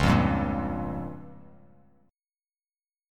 Db6b5 chord